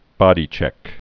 (bŏdē-chĕk)